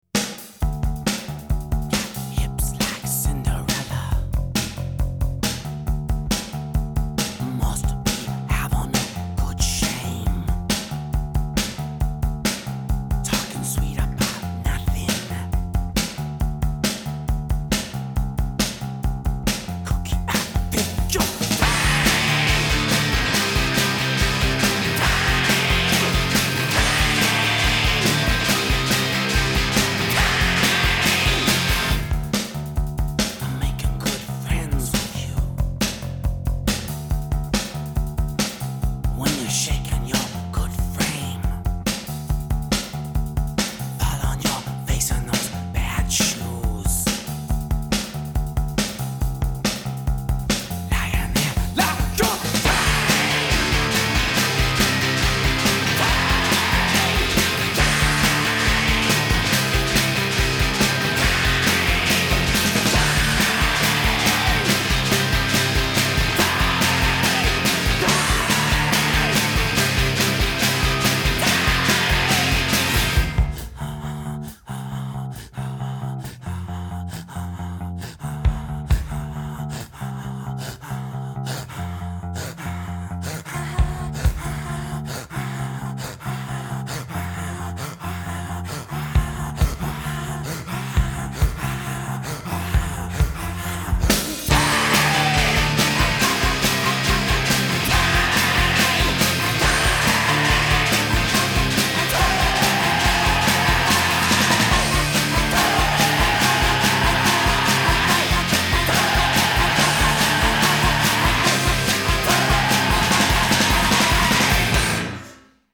Альтернативный рок